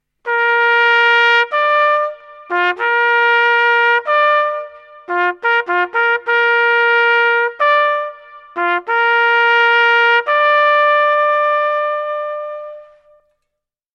Звуки охоты
Охота окончена (охотничья труба)